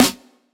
Boom-Bap Snare 69.wav